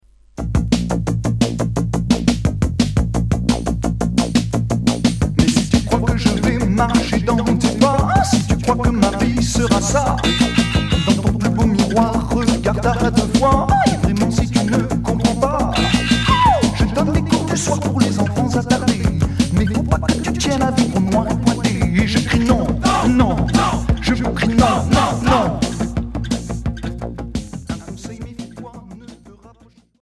New wave Unique 45t retour à l'accueil